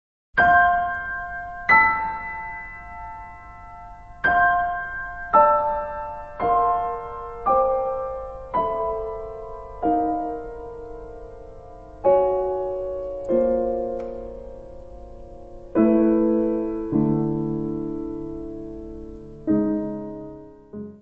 piano
Music Category/Genre:  Classical Music